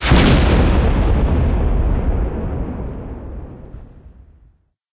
explode.ogg